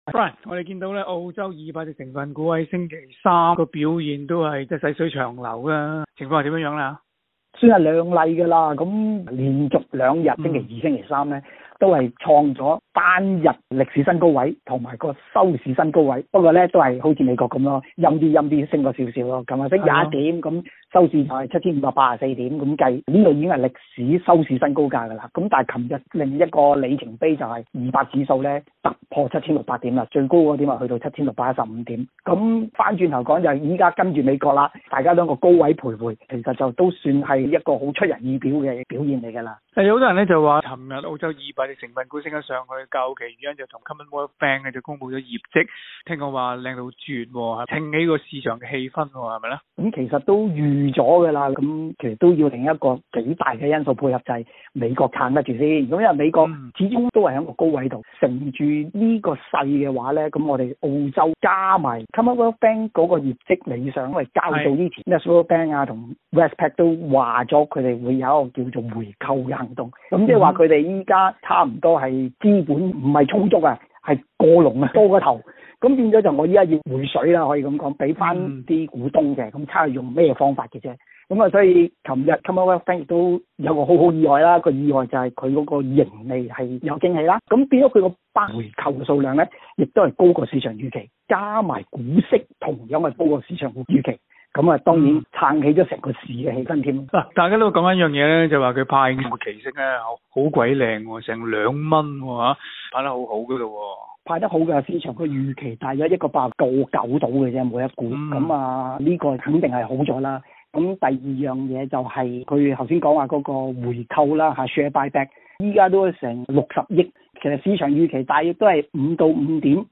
詳情請收聽今天的訪問内容。